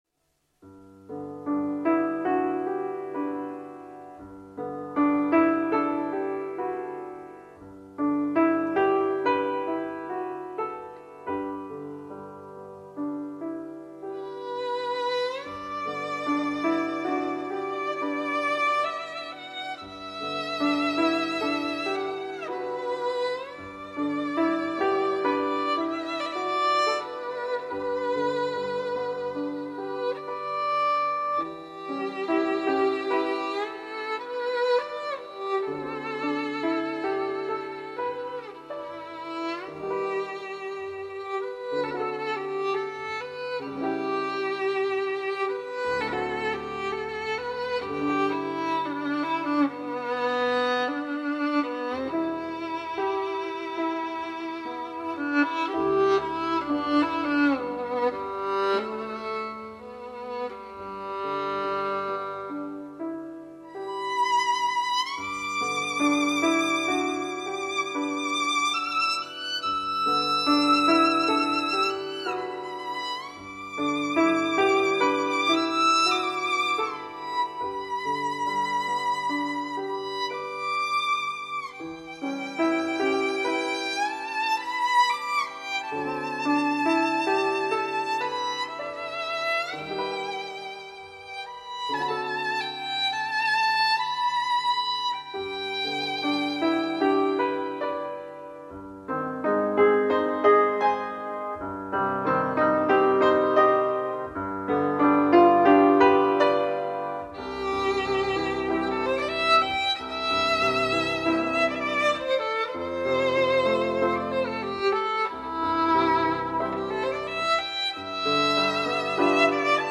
小提琴演奏
演奏风格既热情奔放，又甜美细腻，富于诗情画意、浪漫幻想。
钢琴伴奏版